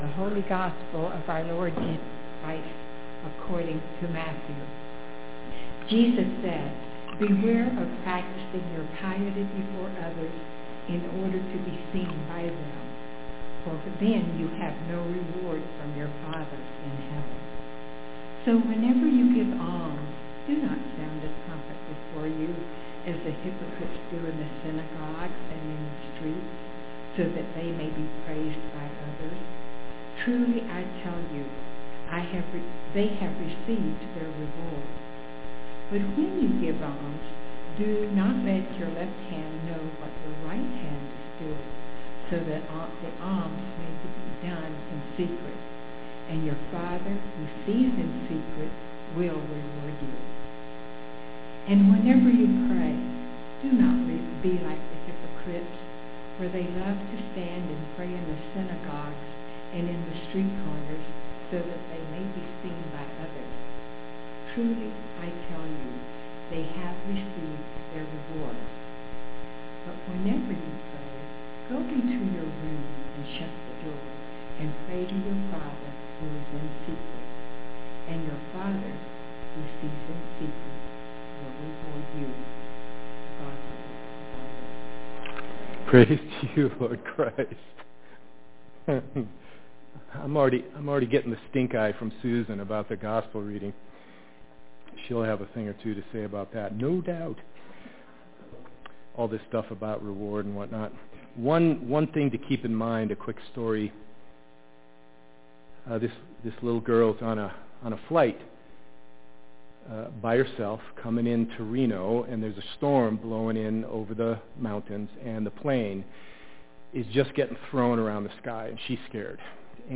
The Bible reading from Matthew 6:1-6 (click to read) is buzzy, but it is Lent, so endure it…or skip to the better audio beginning the message at 1:30